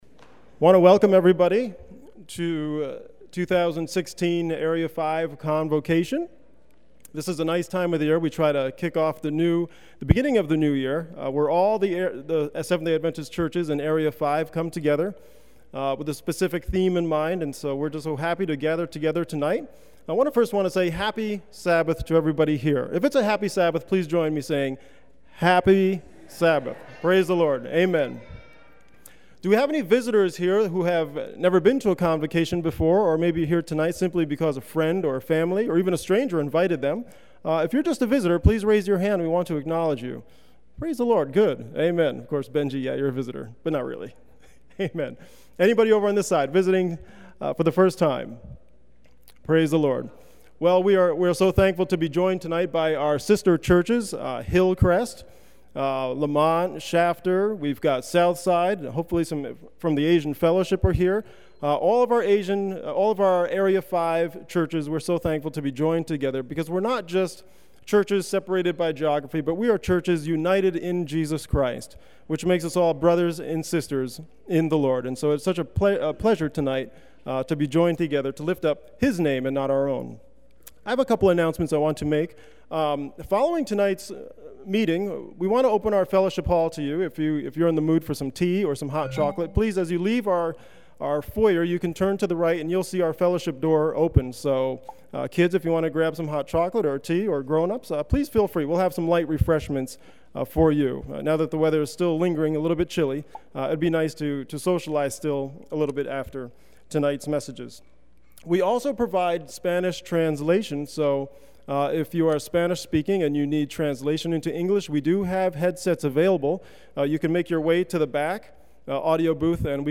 on 2016-02-07 - Sabbath Sermons